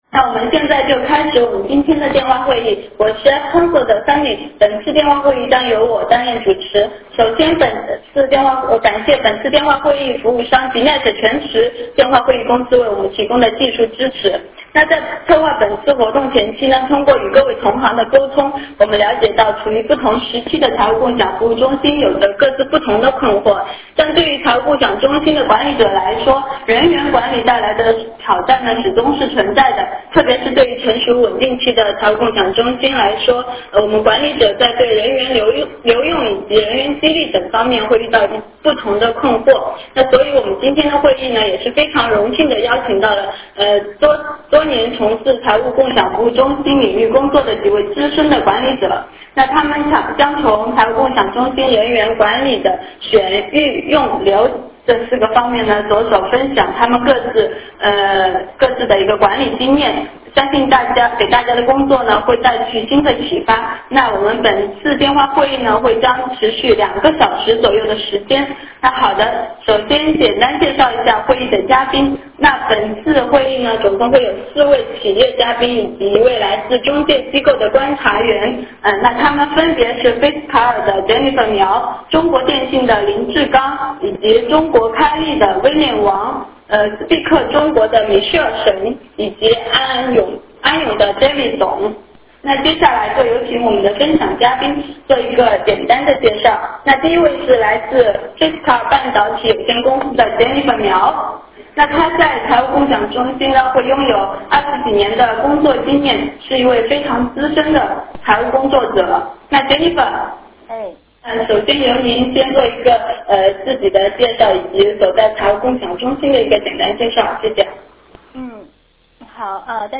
电话会议